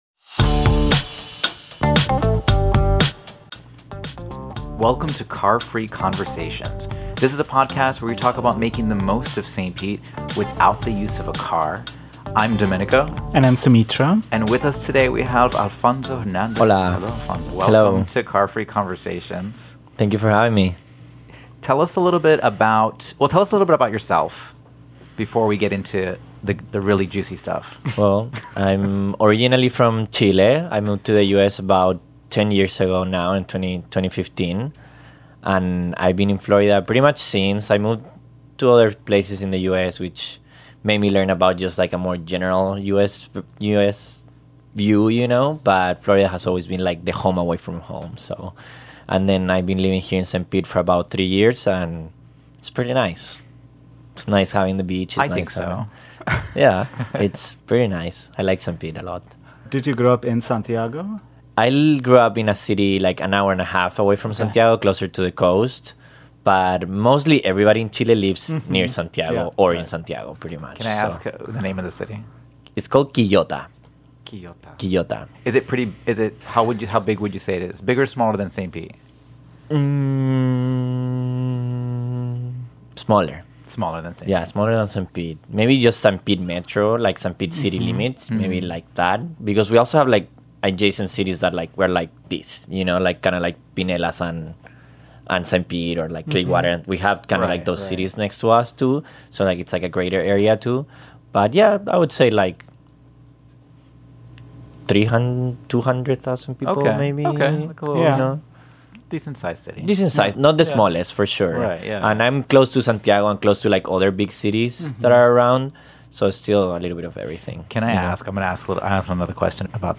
Car-Free Conversations